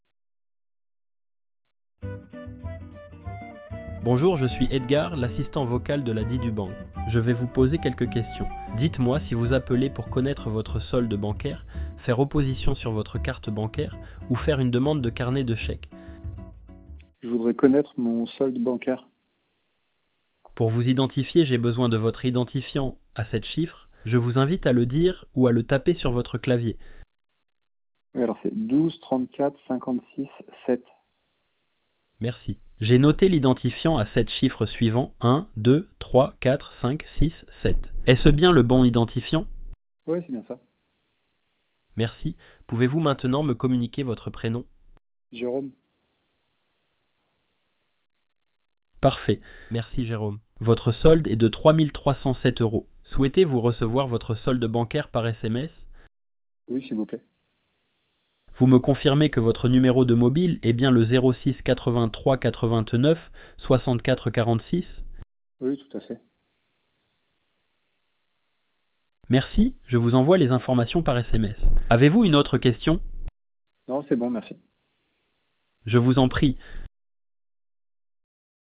Listen to our various callbot demos and try them out for yourself by dialing the numbers shown (at no extra cost).